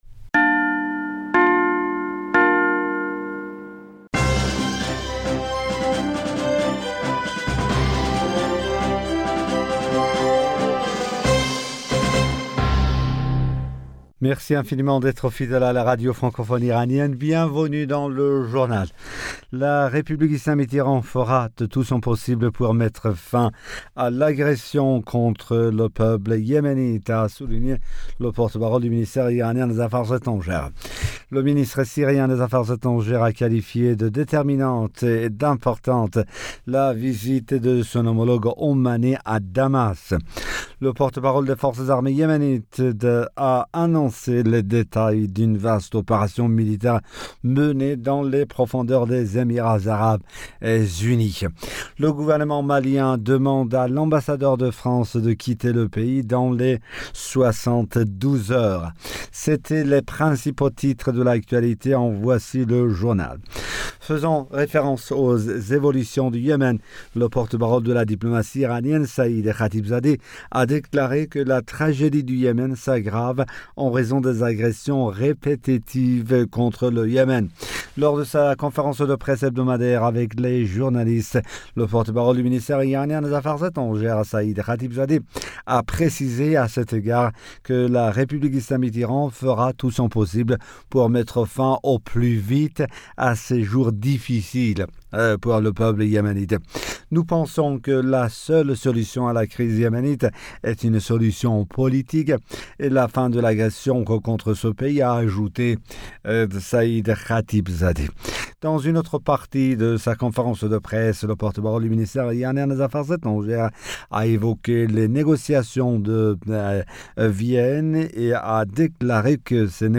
Bulletin d'information Du 01 Fevrier 2022